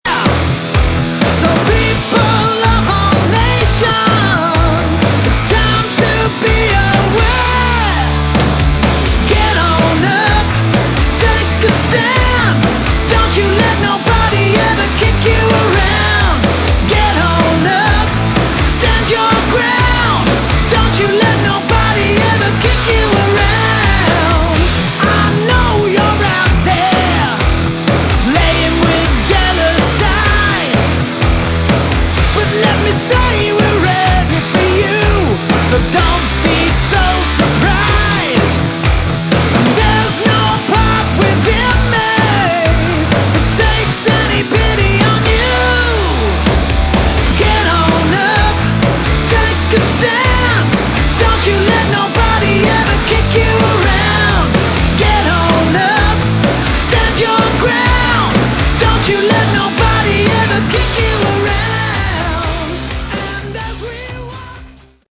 Category: Hard Rock
Vocals
Drums
Guitars
Bass